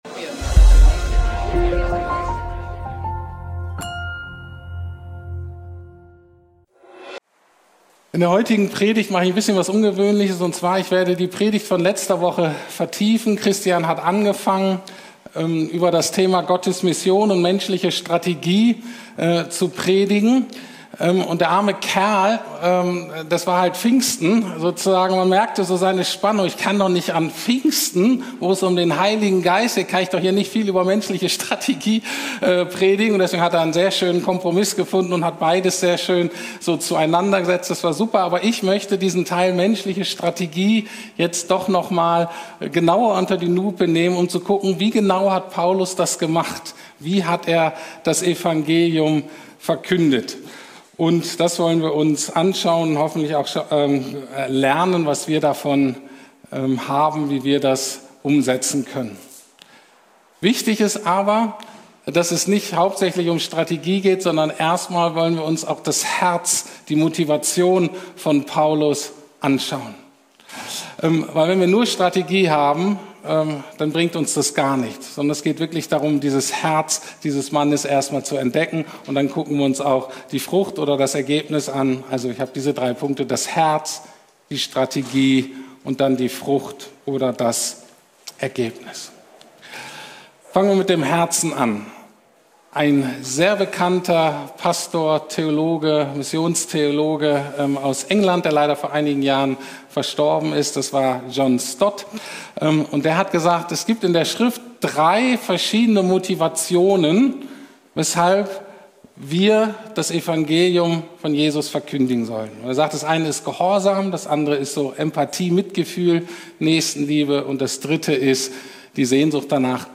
Predigten der LUKAS GEMEINDE
Predigten der LUKAS GEMEINDE in Berlin Schöneberg.